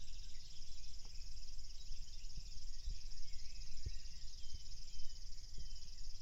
Птицы -> Славковые ->
речной сверчок, Locustella fluviatilis
СтатусПоёт